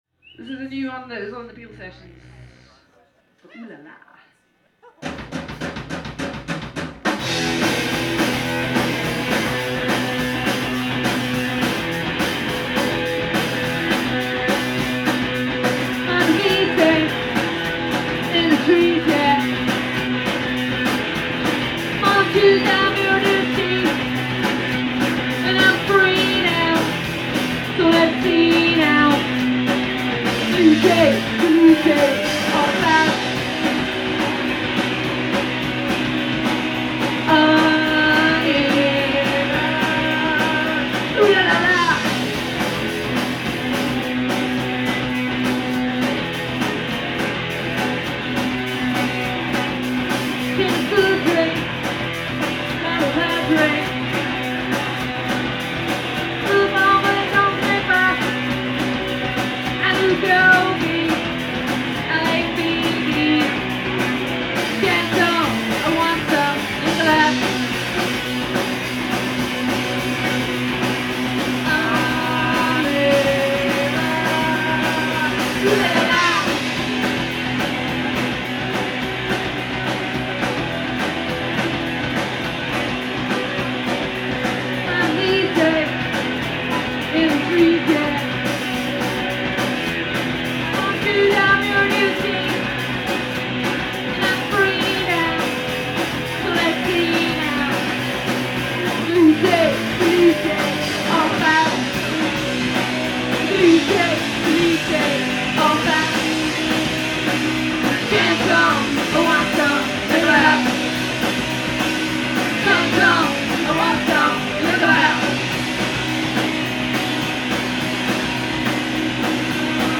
steadily intense drumming
alternatingly subdued and fierce voice
repetitive, rolling bass lines
frenetic guitar noise
Live at PA’s Lounge